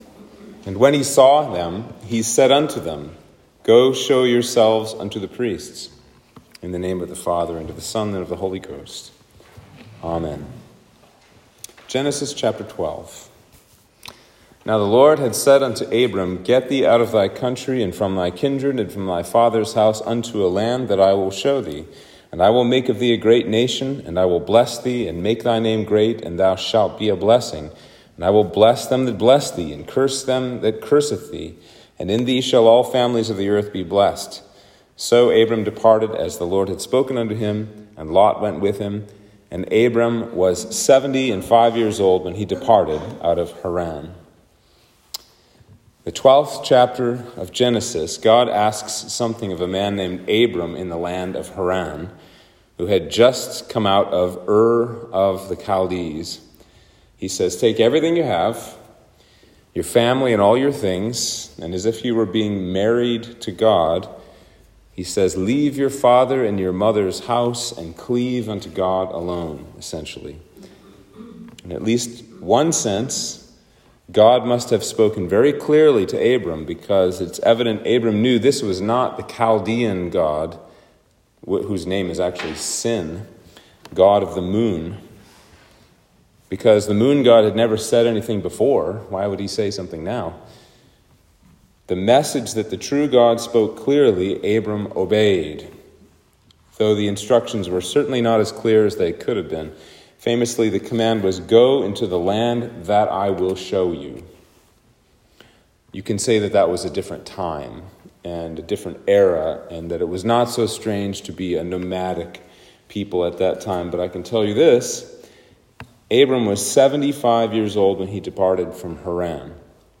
Sermon for Trinity 14